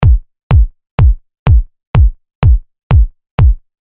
描述：只是为你提供一个小的突破性鼓点
标签： 125 bpm Breakbeat Loops Drum Loops 661.54 KB wav Key : Unknown
声道立体声